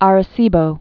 A·re·ci·bo
rə-sēbō)